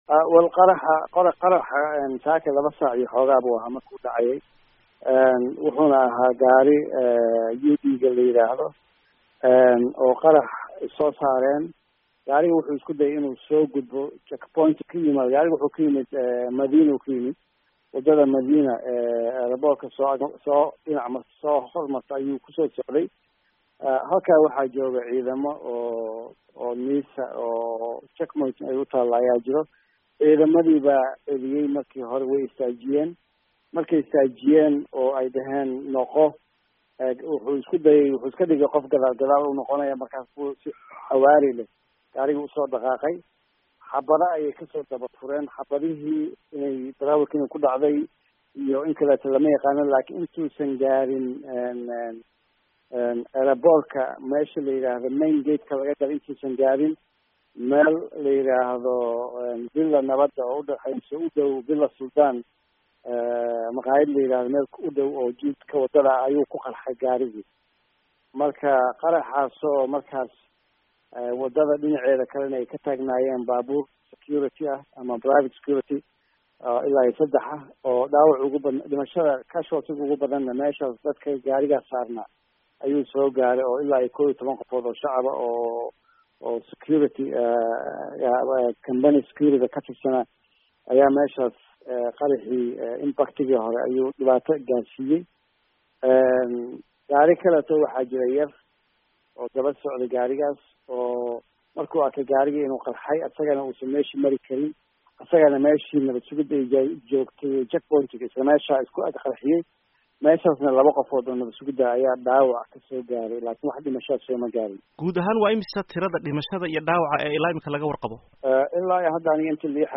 Wareysi: Wasiirka Amniga Qaranka